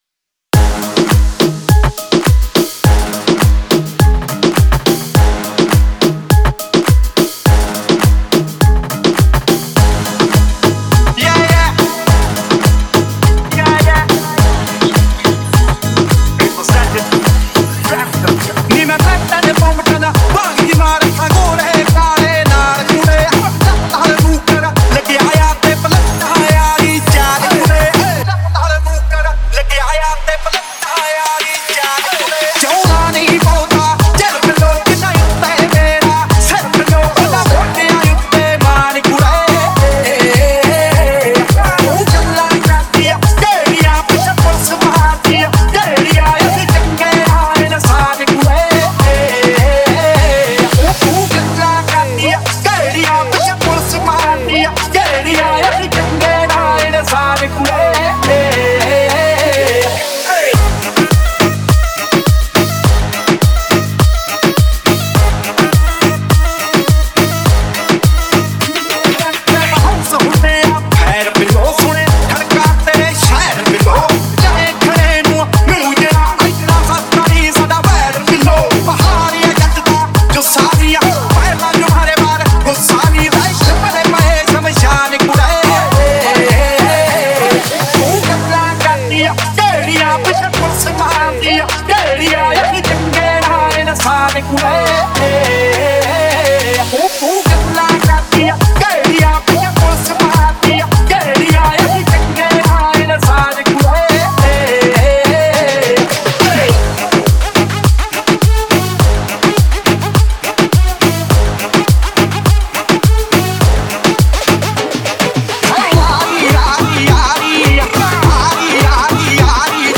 is a old Punjabi song from the 2020 album